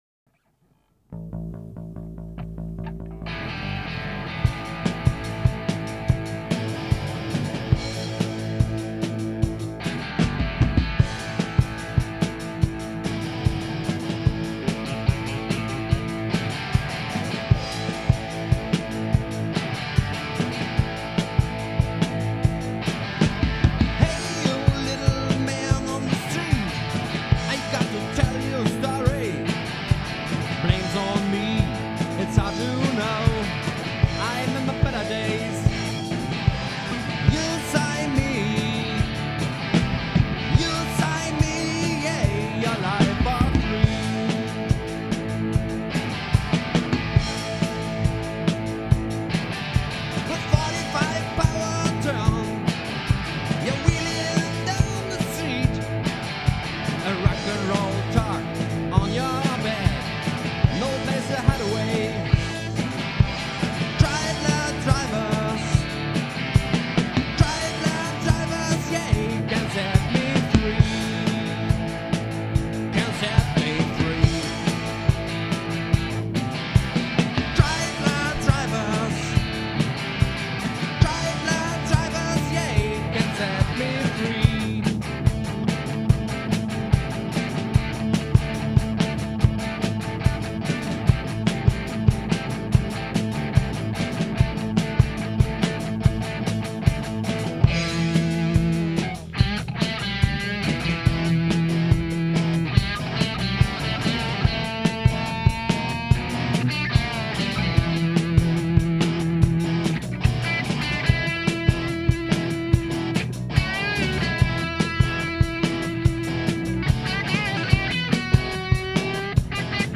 Rhythm Guitar
Drums
Bass
Lead Guitar, Vocals